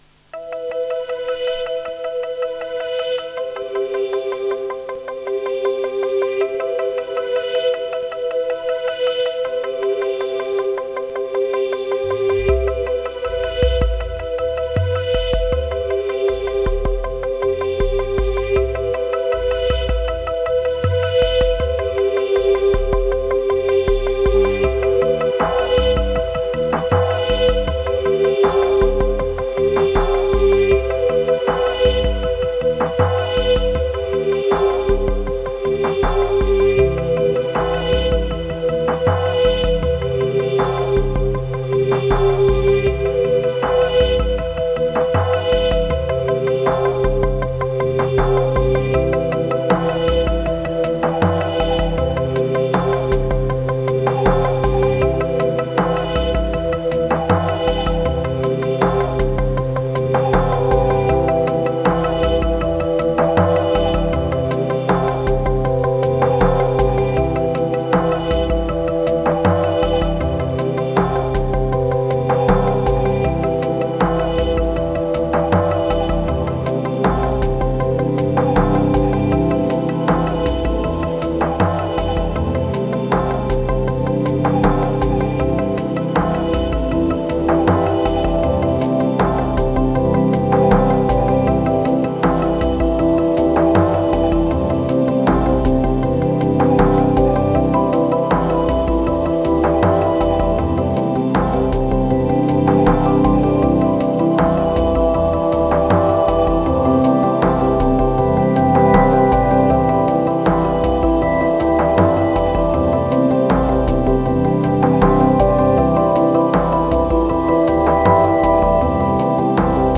377📈 - 91%🤔 - 79BPM🔊 - 2025-08-14📅 - 521🌟
Low energy ambient beats.
Moods of a mid summer longweek-end span.